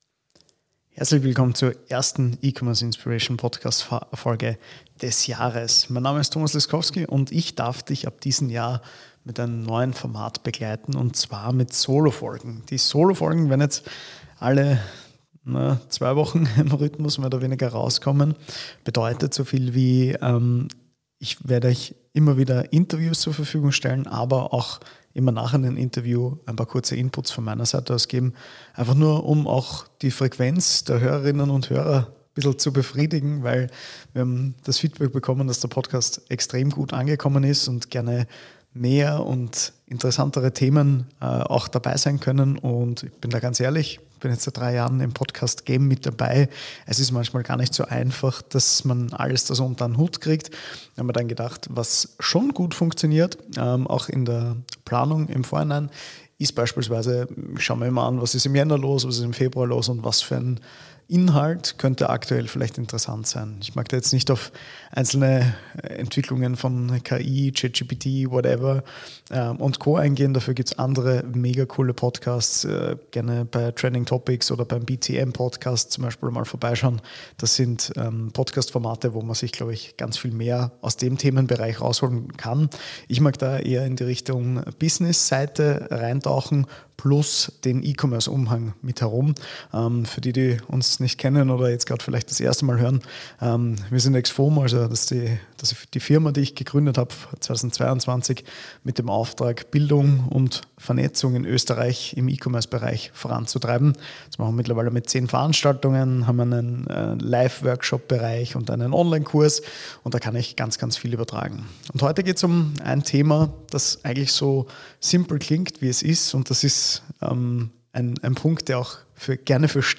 In der ersten Solo-Episode des Jahres gibt’s klare und ehrliche Insights